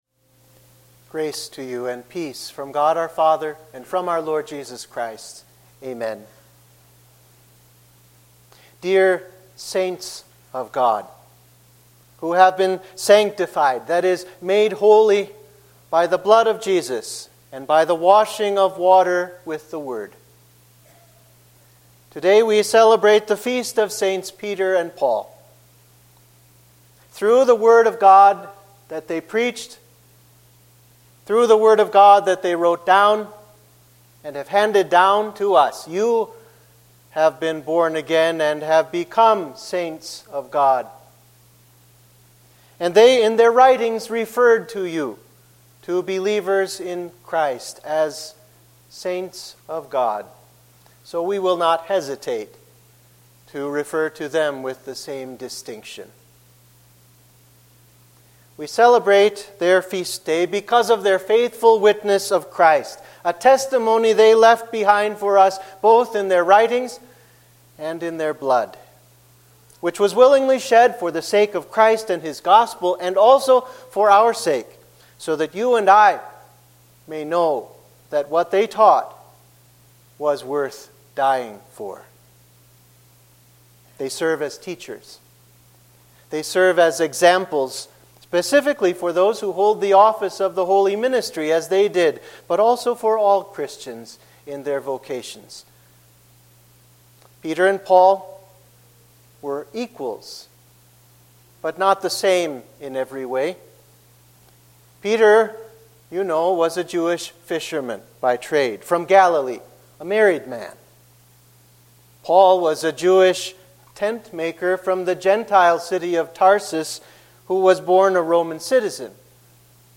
Sermon for the Feast of Sts. Peter and Paul